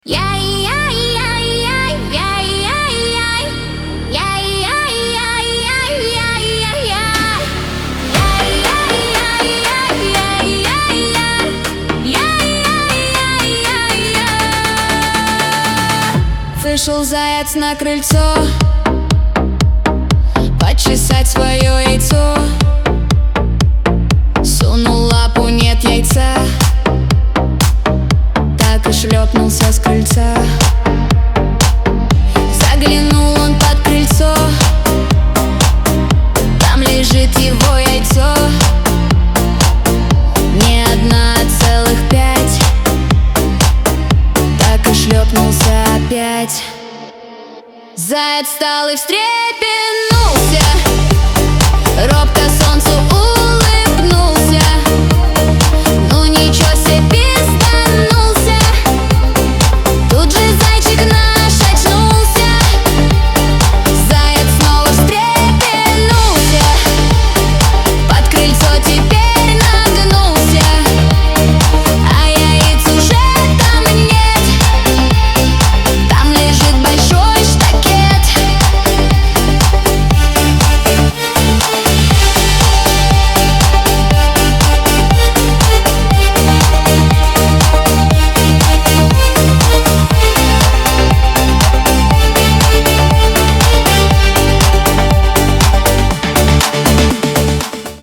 Песня наполнена юмором и абсурдом, создавая комичный эффект.